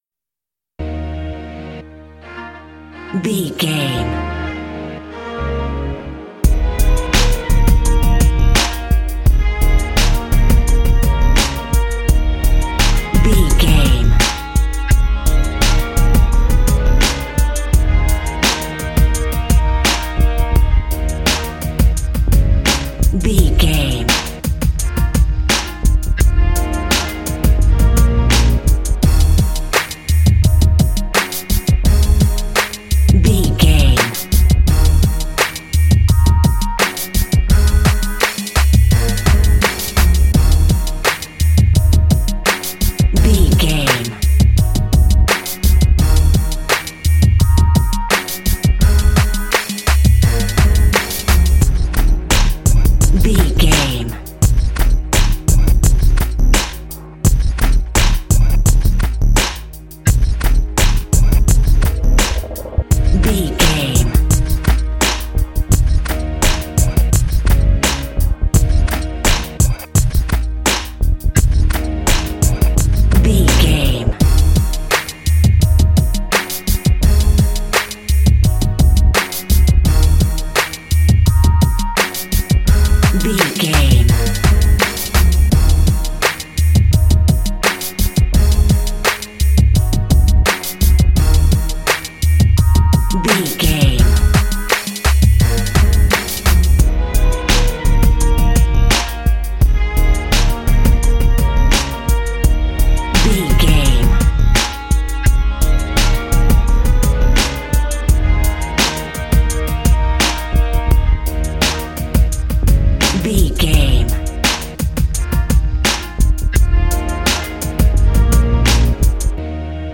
Epic / Action
Aeolian/Minor
E♭
drum machine
synthesiser
hip hop
Funk
neo soul
acid jazz
energetic
bouncy
Triumphant
funky